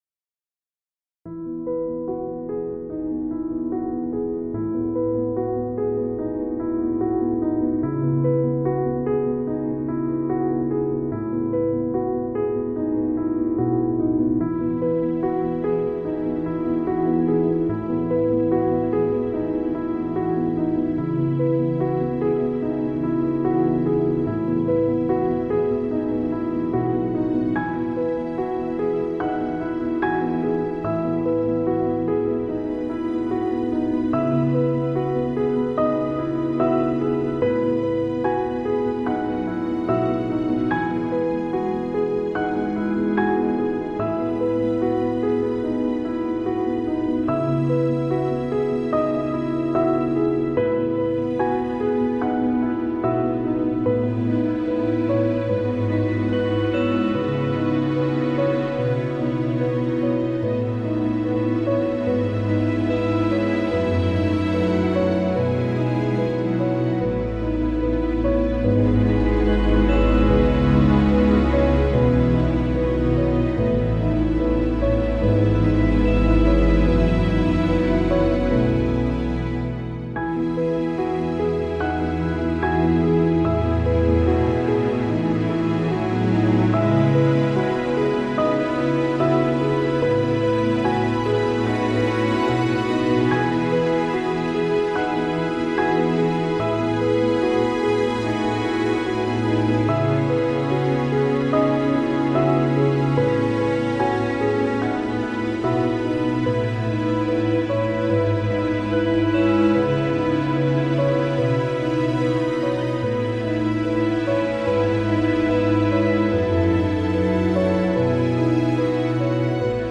Brücken-Idylle Sanfte & Nachtstimmung | Schlaf- & Entspannungs-BGM